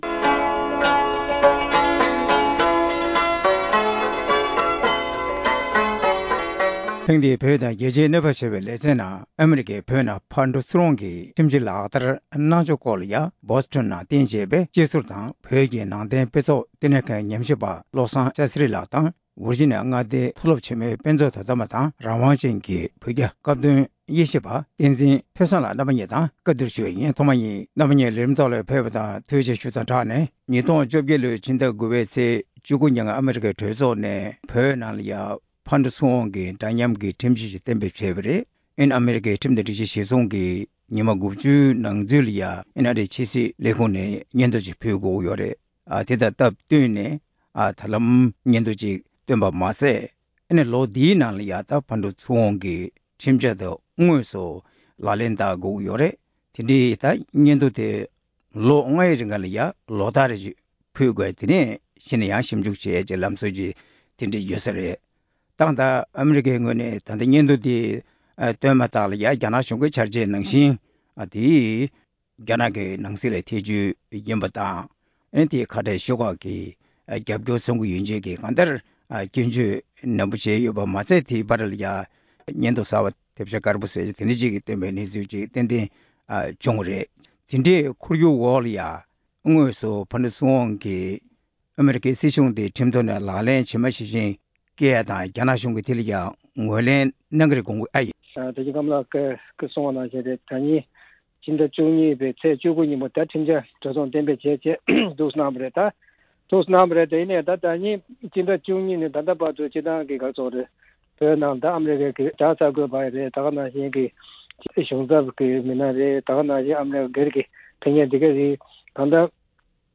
ཨ་མི་རི་ཀས་བོད་ནང་ཕར་བསྐྱོད་ཚུར་བསྐྱོད་ཁྲིམས་གཞི་ལག་ལེན་གནང་ཕྱོཌ་དང་འབྲེལ་བའི་སྐོར་གླེང་མོལ་གནང་བ།